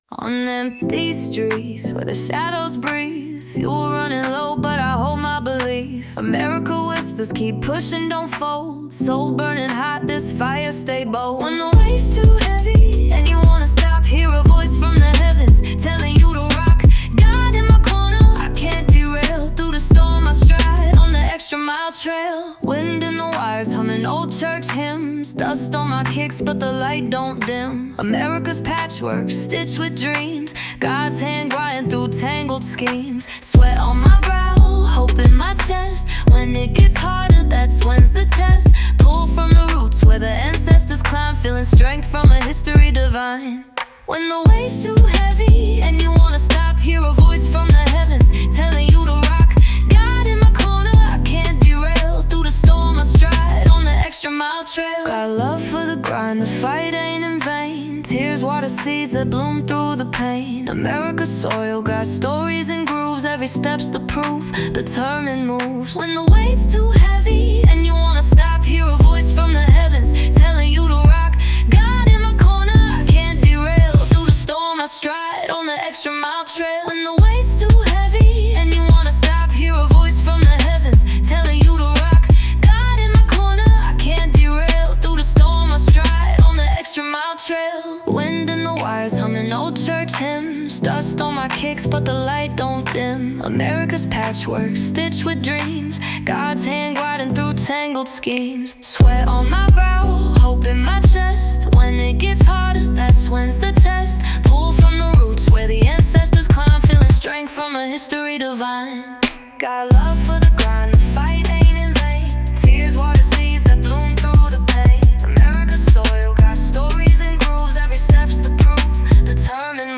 Christian, R&B